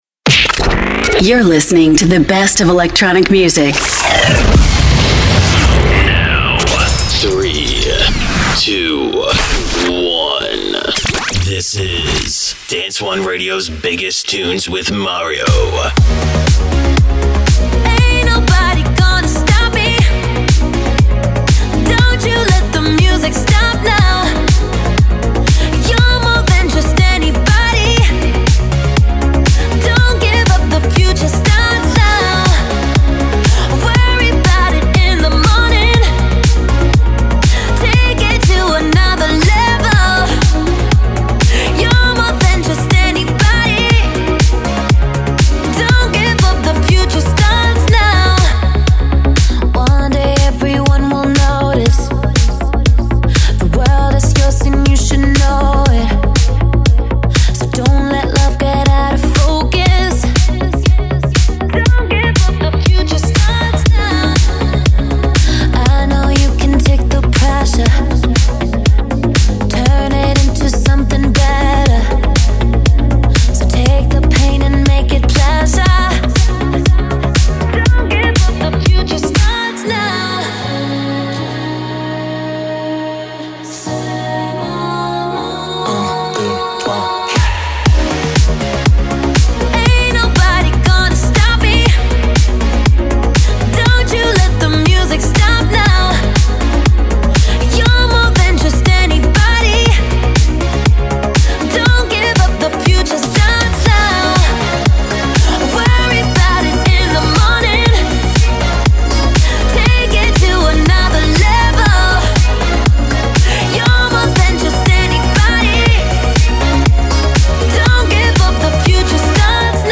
Dance anthems that rule the dance and electronic scene